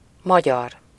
Ääntäminen
France: IPA: [ɔ̃.ɡʁwa]